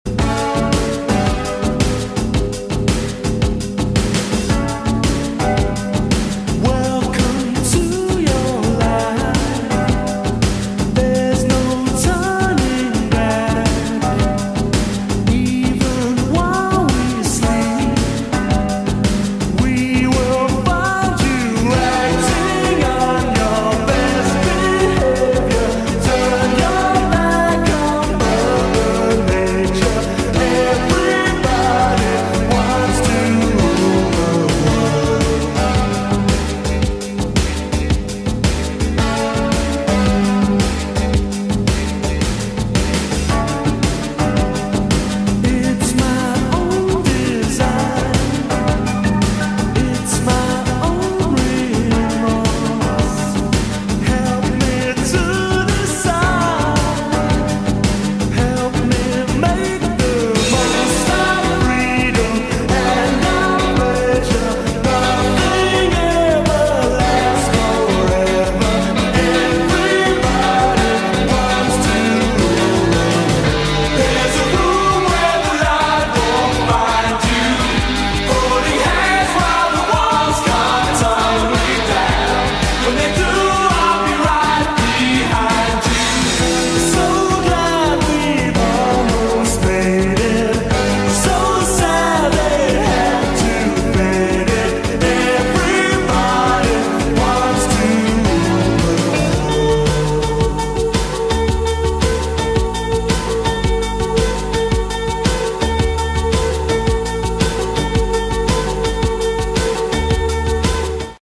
idleloop_mechtwo.wav